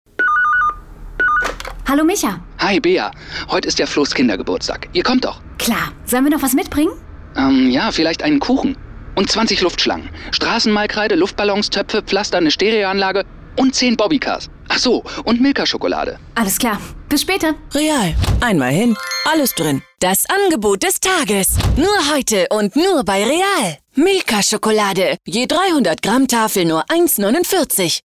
deutscher Sprecher, Dialekt: norddeutsches Platt
Sprechprobe: eLearning (Muttersprache):
german voice over artist